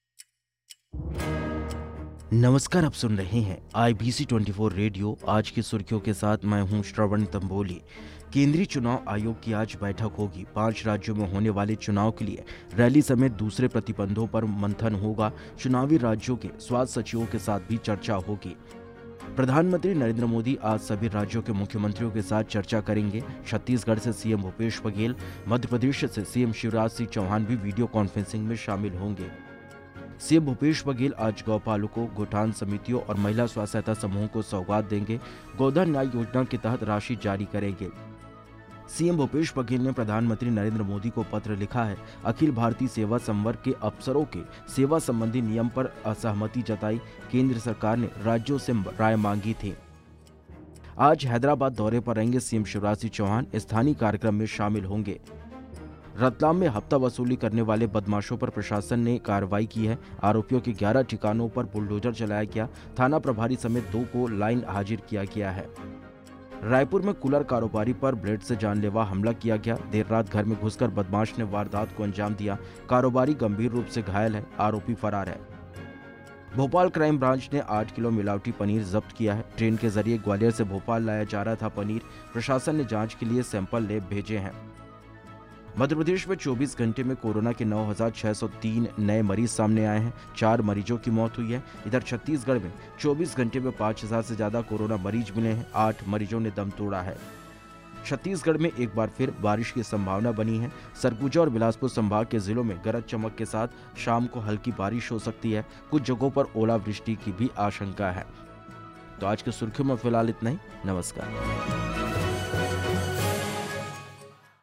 Today's headlines
आज की सुर्खियां